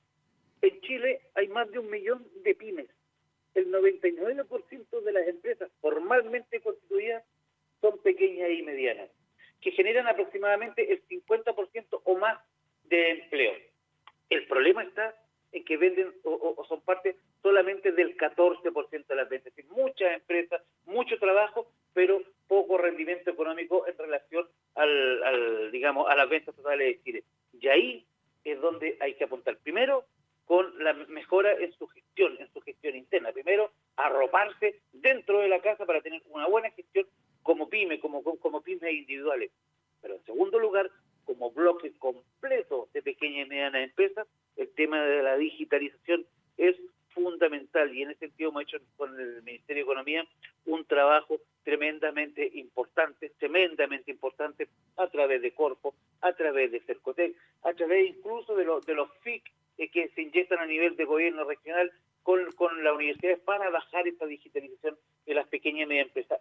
En conversación con Nuestra Pauta, el seremi de Economía, Mauricio Gutiérrez, destacó la importancia de retomar esta actividad, suspendida en 2020 por la crisis sanitaria, y recordó que su objetivo fundamental es reconocer la labor de las empresas de menor tamaño y potenciar su desarrollo dentro de la economía local.